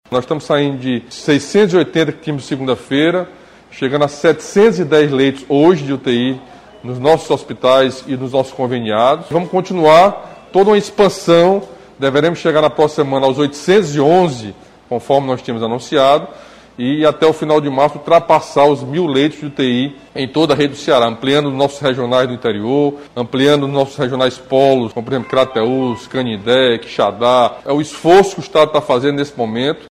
O anúncio foi realizado nesta sexta-feira (26) durante transmissão ao vivo nas redes sociais.
Camilo Santana destacou que a rede de saúde do Estado deverá contar com mais de mil leitos de UTI Covid-19 até o fim de março.